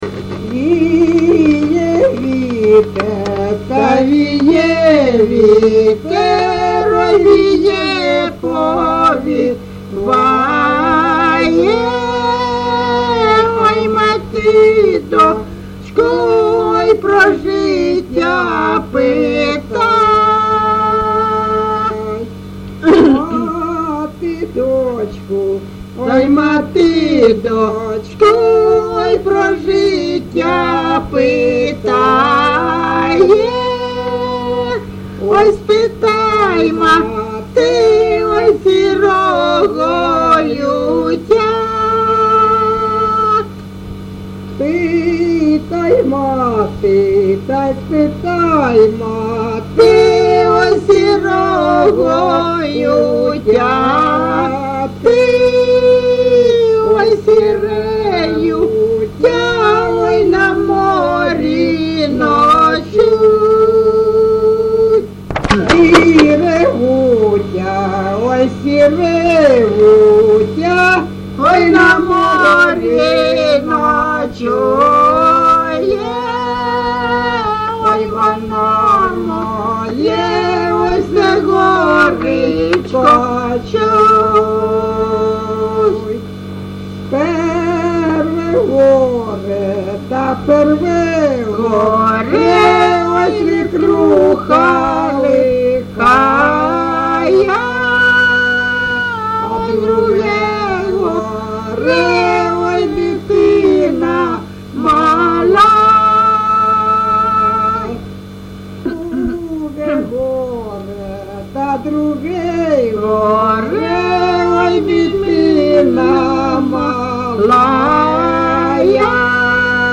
ЖанрПісні з особистого та родинного життя
Місце записус. Калинове Костянтинівський (Краматорський) район, Донецька обл., Україна, Слобожанщина